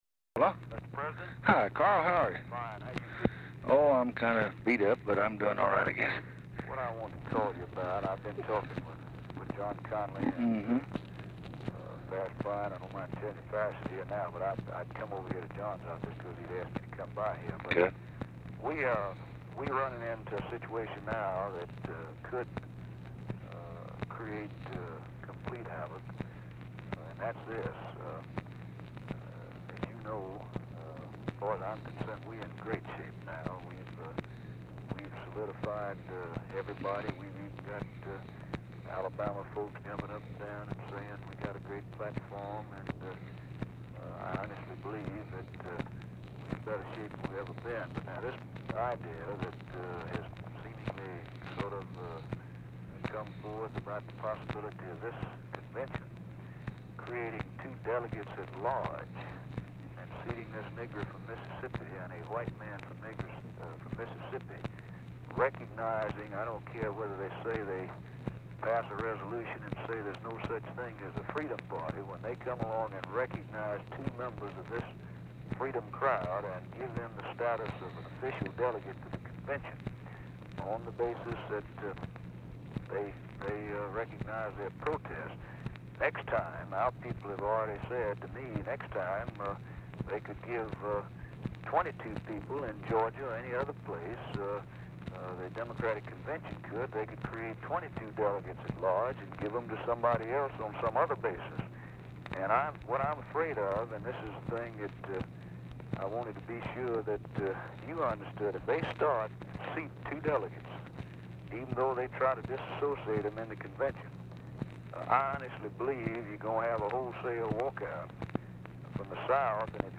Telephone conversation # 5183, sound recording, LBJ and CARL SANDERS, 8/25/1964, 4:32PM
SANDERS IS IN ATLANTIC CITY
Format Dictation belt